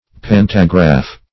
Pantagraph \Pan"ta*graph\, n.